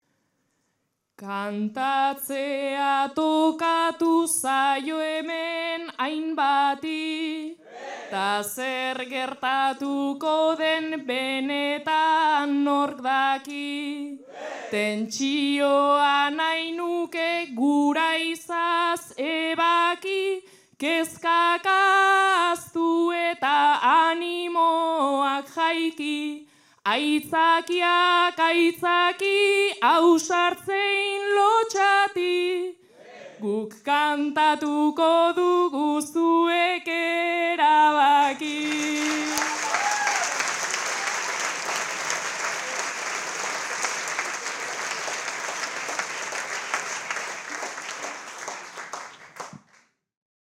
Zarautz (Gipuzkoa)
Agurra.